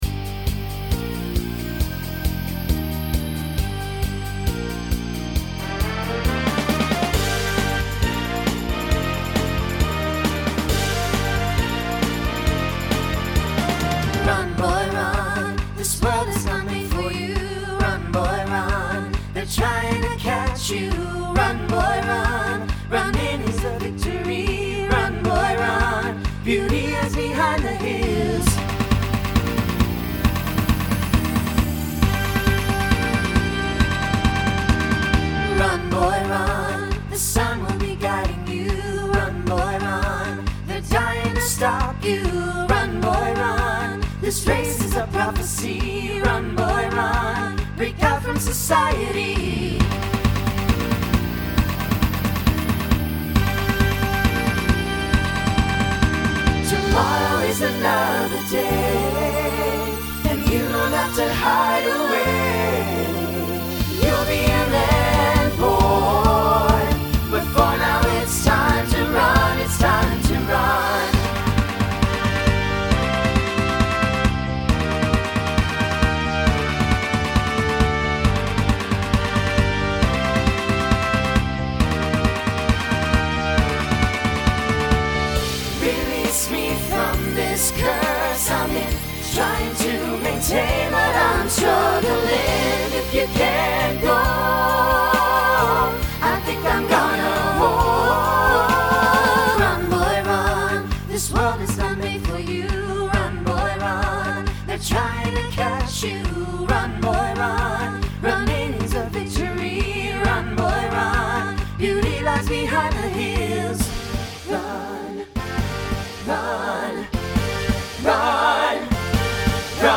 Voicing SATB Instrumental combo Genre Pop/Dance , Rock
2010s Show Function Mid-tempo